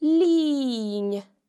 The slender nn can be heard in linn (a century):